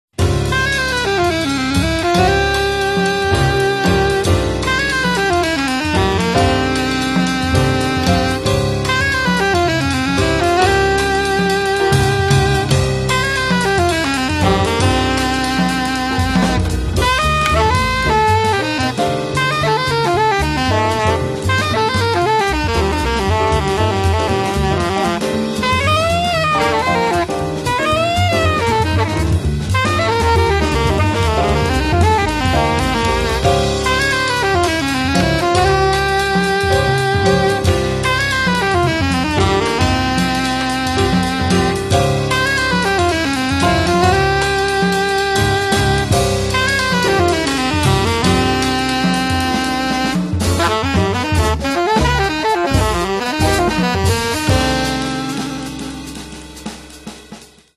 sax alto e sopranino
pianoforte
contrabbasso
batteria